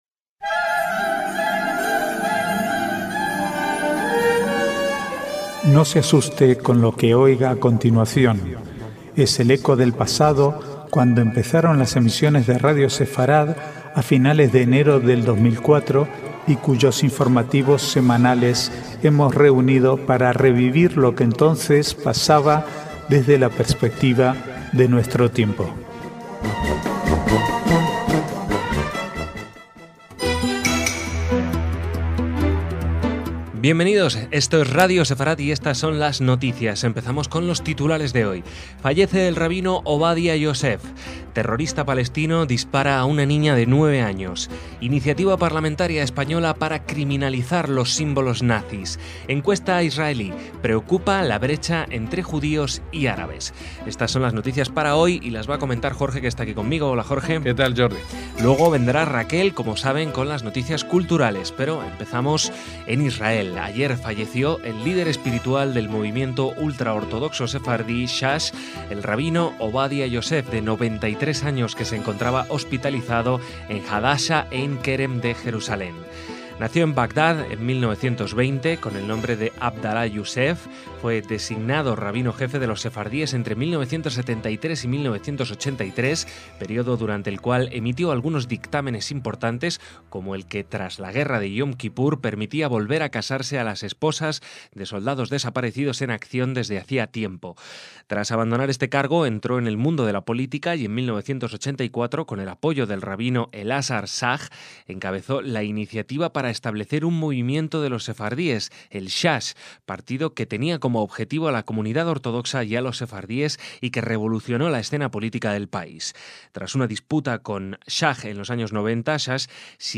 Archivo de noticias del 8 al 11/10/2013